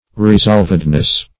Search Result for " resolvedness" : The Collaborative International Dictionary of English v.0.48: Resolvedness \Re*solv"ed*ness\, n. Fixedness of purpose; firmness; resolution.